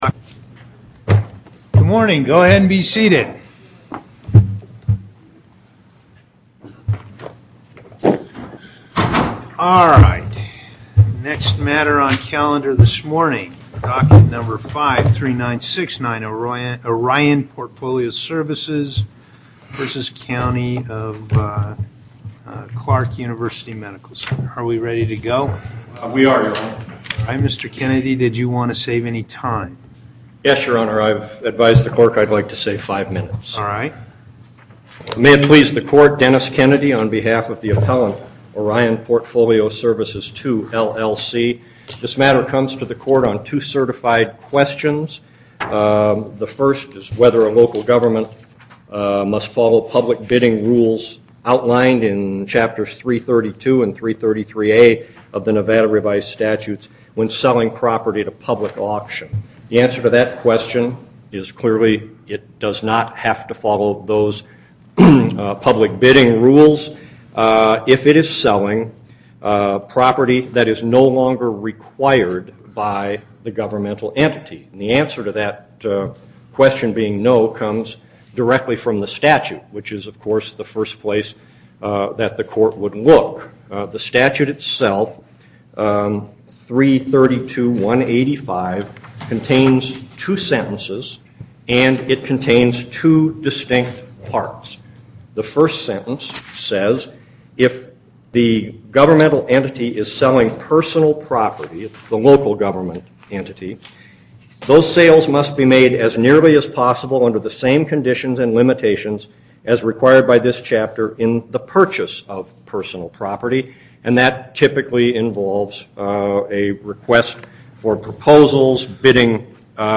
Location: Carson City Before the En Banc Court; Chief Justice Parraguirre Presiding